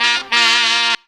GROWL RIFF 2.wav